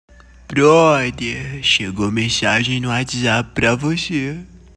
Categoria: Toques